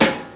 • Classic Hip-Hop Acoustic Snare Sample F Key 100.wav
Royality free snare drum sound tuned to the F note. Loudest frequency: 2269Hz
classic-hip-hop-acoustic-snare-sample-f-key-100-OSG.wav